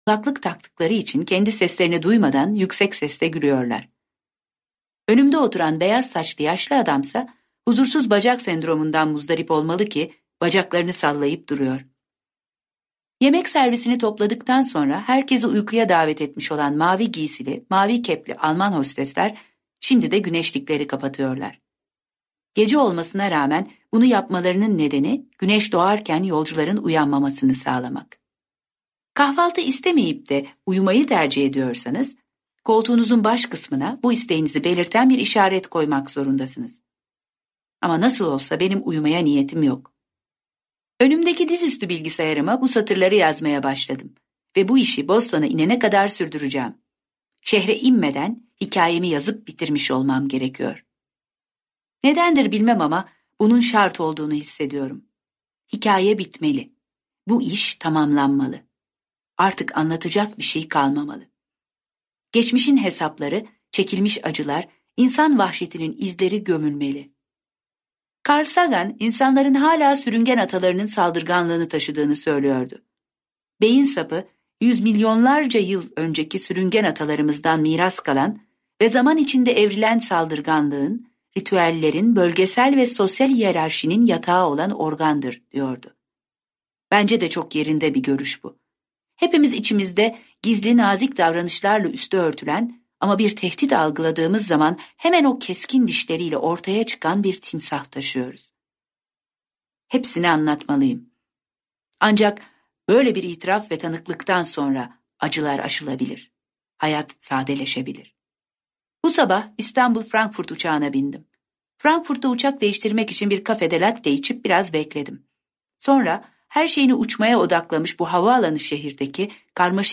Serenad - Seslenen Kitap